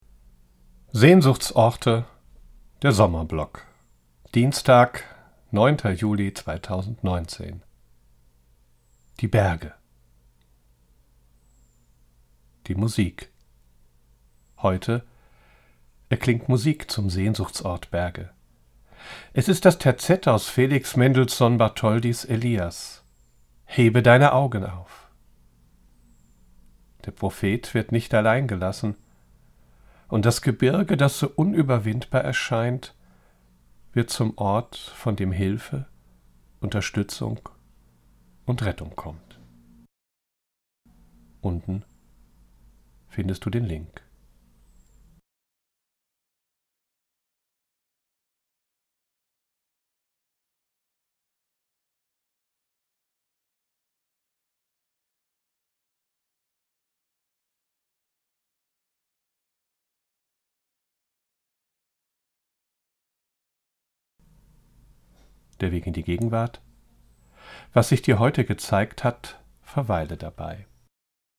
Es ist das Terzett aus Felix Mendelssohn-Bartholdys Elias: „Hebe deine Augen auf“. Der Prophet wird nicht allein gelassen und das Gebirge, das so unüberwindbar erscheint, wird zum Ort, von dem Hilfe, Unterstützung und Rettung kommt.